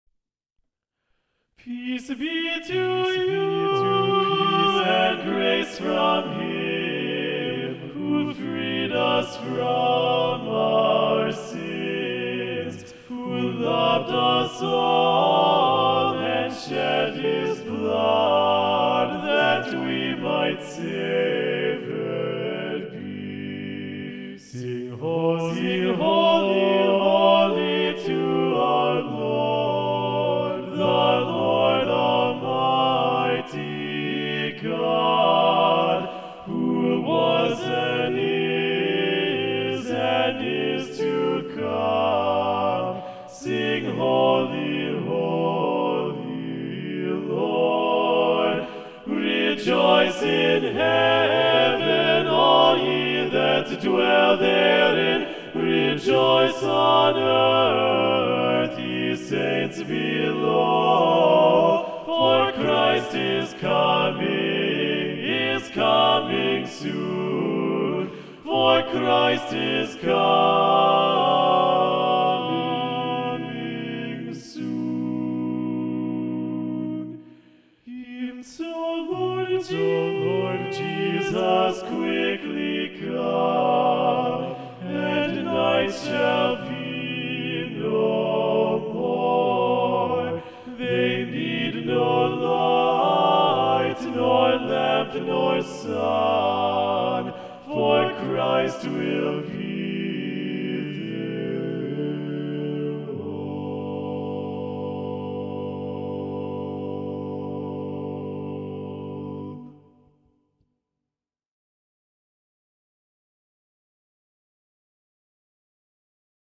TTBB a cappella Level